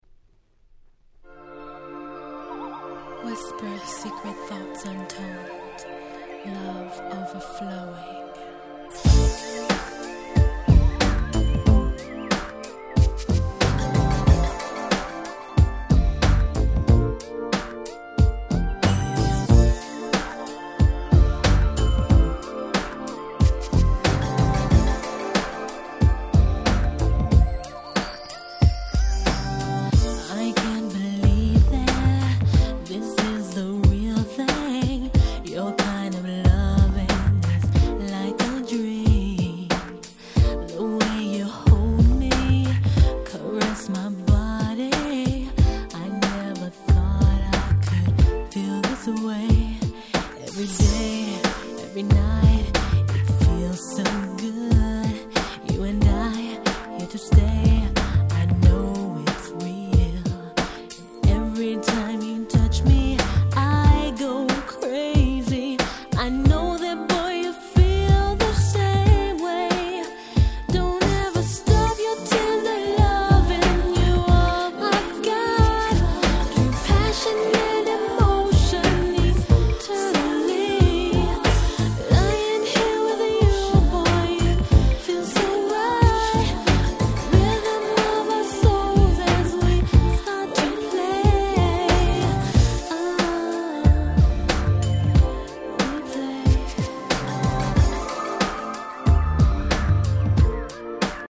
97' Nice Japanese R&B !!
国産ユニットながら、全編英語での歌詞、サウンドも国外物に引けを取らない素晴らしい出来！